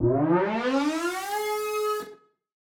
Index of /musicradar/future-rave-samples/Siren-Horn Type Hits/Ramp Up
FR_SirHornE[up]-A.wav